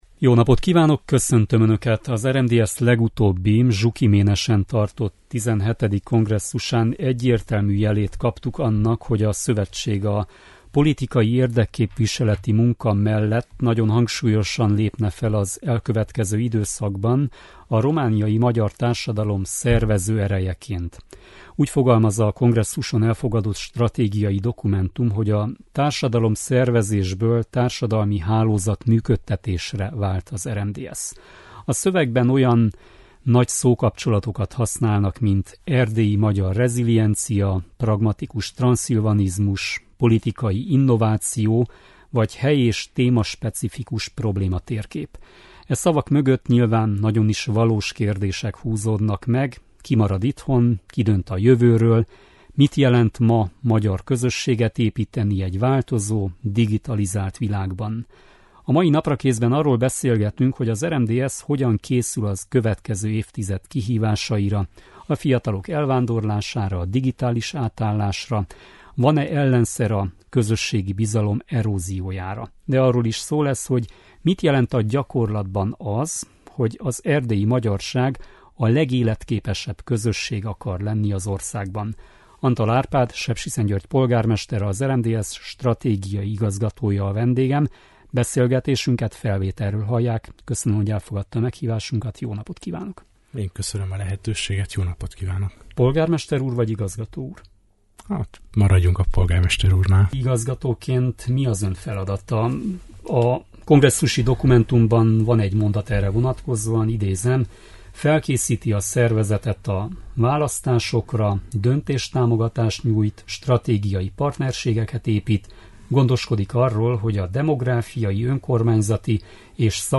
Antal Árpád, Sepsiszentgyörgy polgármestere, az RMDSZ stratégiai igazgatója a vendégünk.